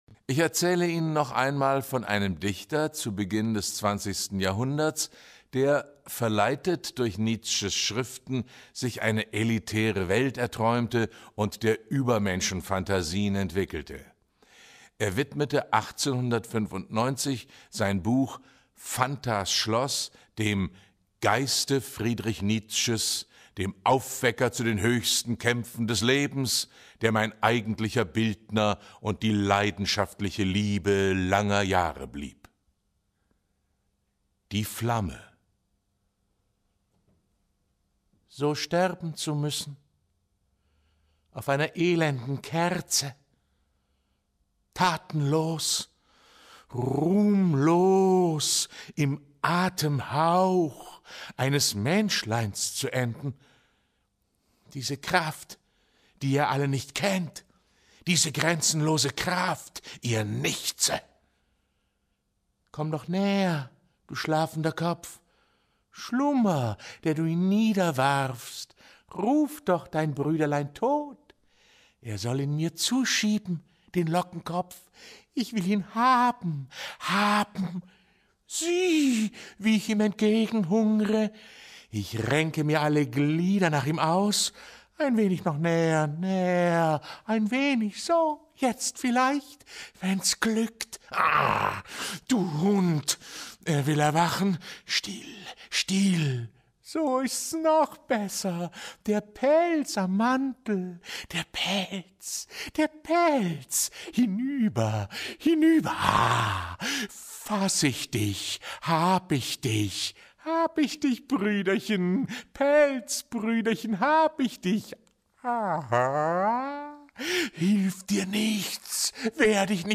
Der bekannte Jurist und Politiker Claudio Zanetti aus der Schweiz referierte auf dem Kongress »Frieden und Dialog« 2025 in Liebstedt. In seinem Vortrag beleuchtete der Redner die besondere Form der Gewaltenteilung in seinem Land: Sie beruht auf Föderalismus – der Machtverteilung zwischen Bund, Kantonen und Gemeinden – und auf der direkten Demokratie mit Volksinitiativen und Referenden.